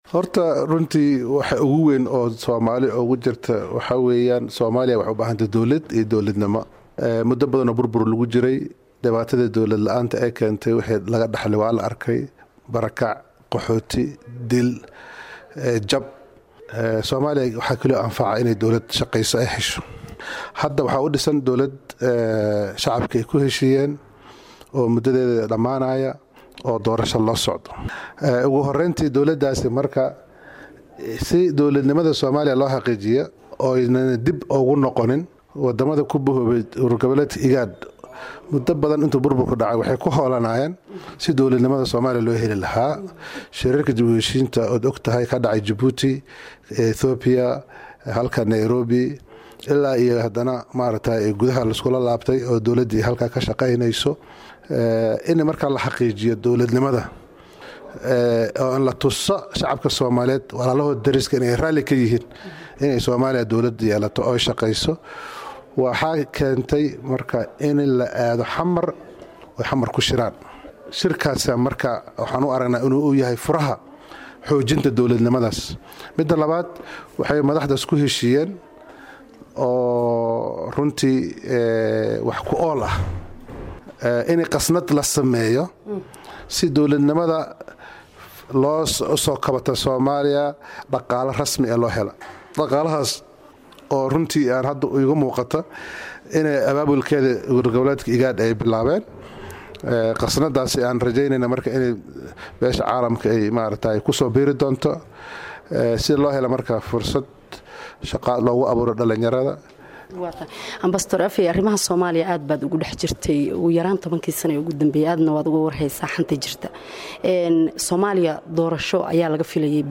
Wareysi: Maxamed Cabdi Afeey